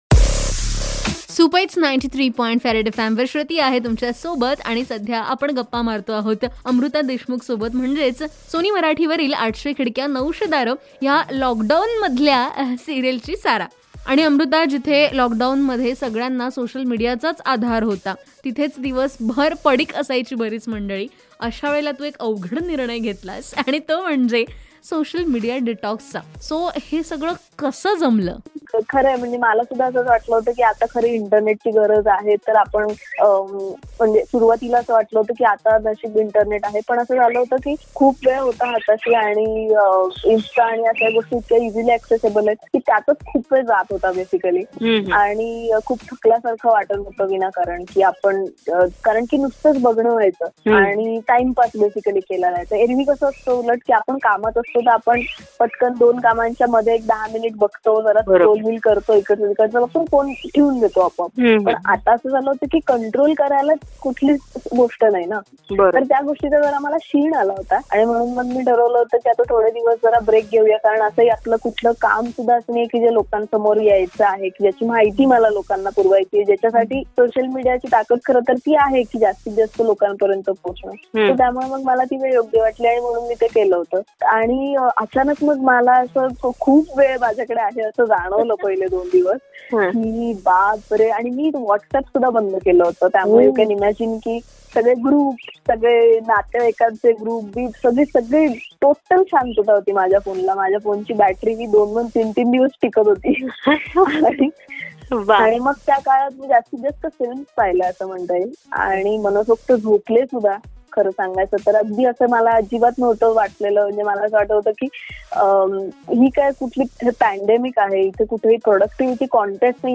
INTERVIEW - PART 1